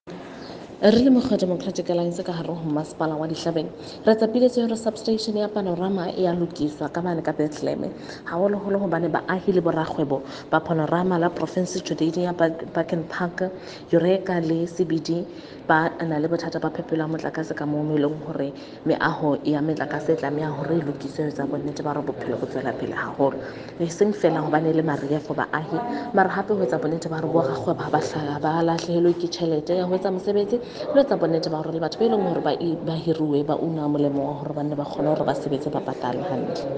Sesotho by Karabo Khakhau MP.
Sotho-voice-Karabo.mp3